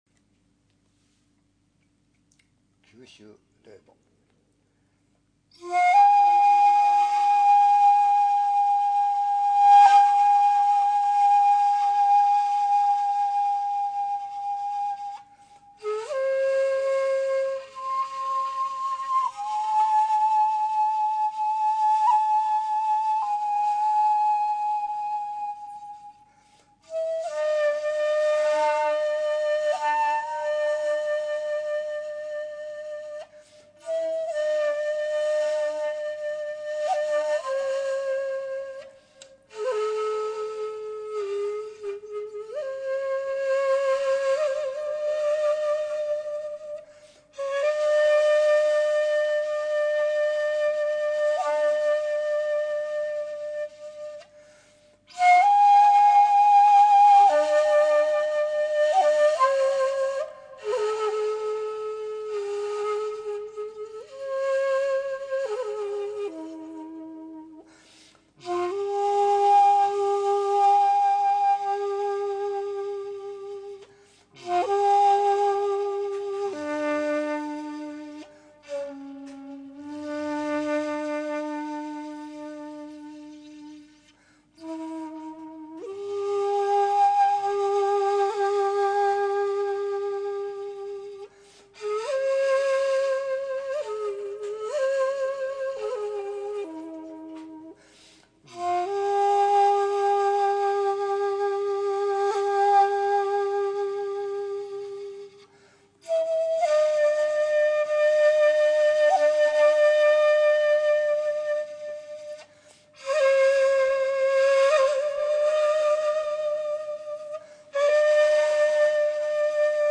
古典本曲
九州鈴慕8寸